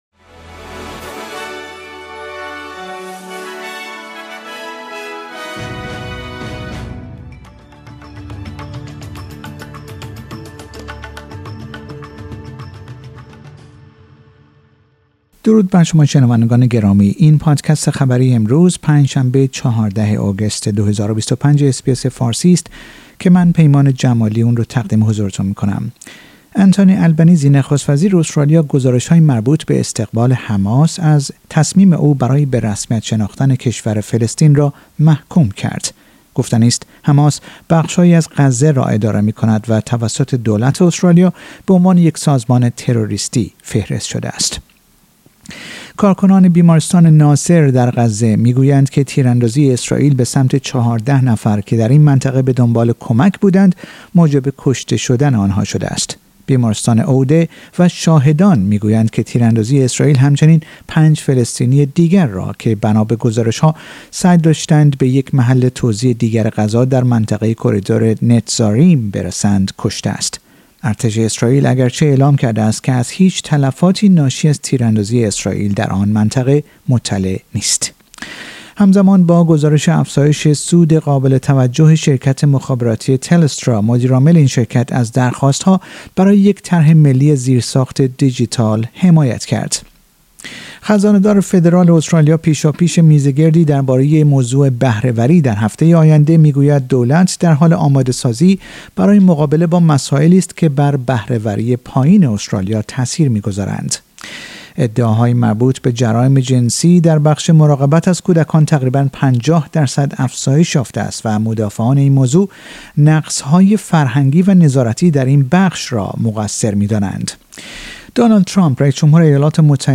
در این پادکست خبری مهمترین اخبار هفته منتهی به پنج شنبه ۱۴ آگوست آمده است.